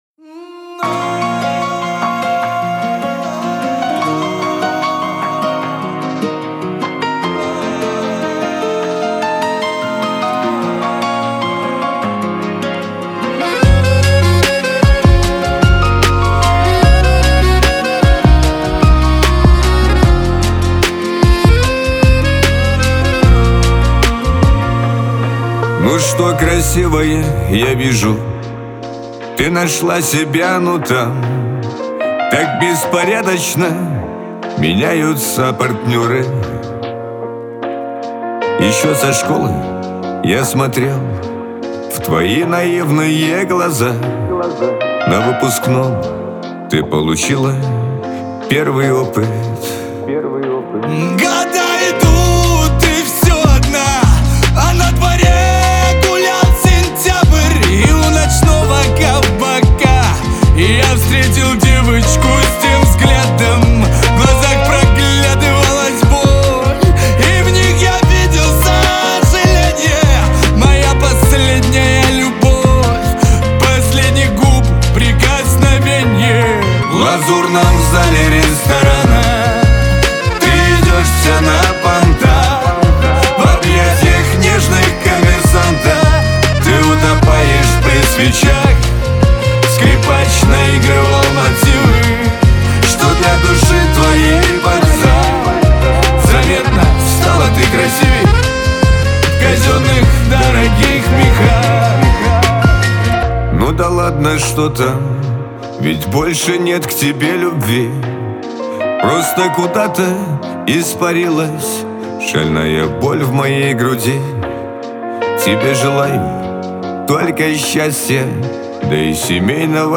Лирика
Шансон
дуэт